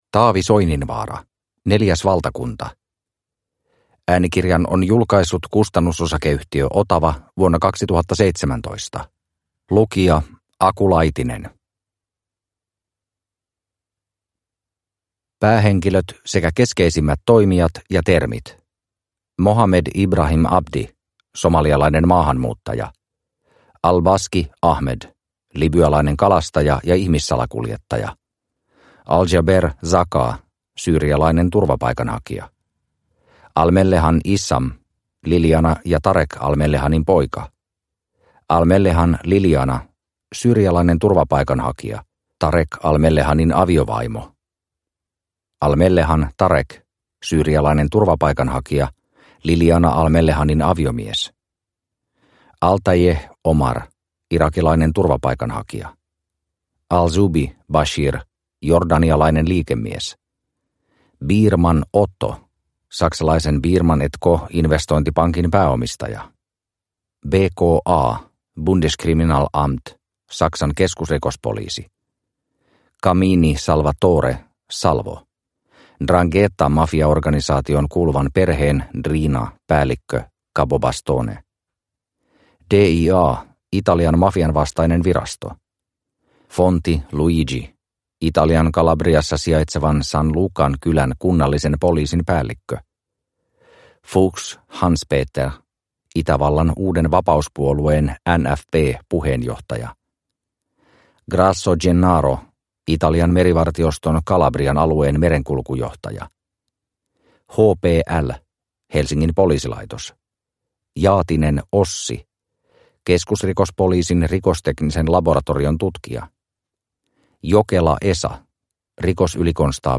Neljäs valtakunta – Ljudbok – Laddas ner